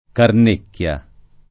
Carnecchia [ karn % kk L a ] cogn.